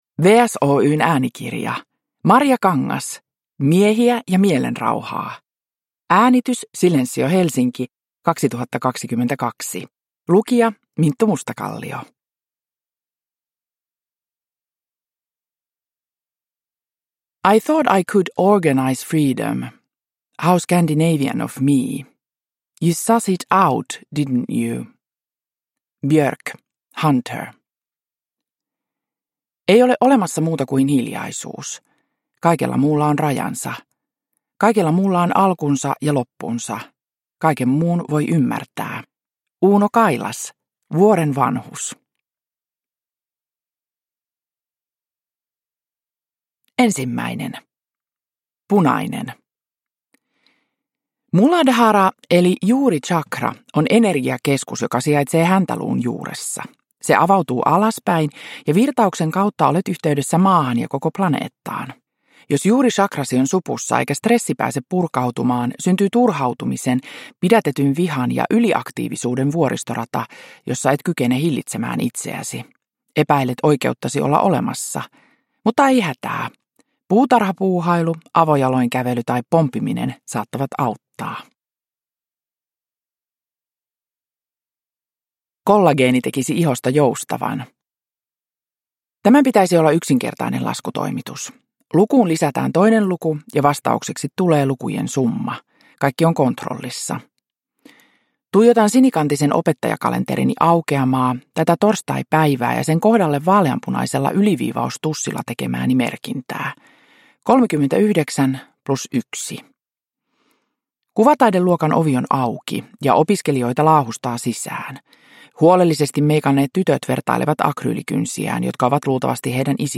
Miehiä ja mielenrauhaa – Ljudbok – Laddas ner
Uppläsare: Minttu Mustakallio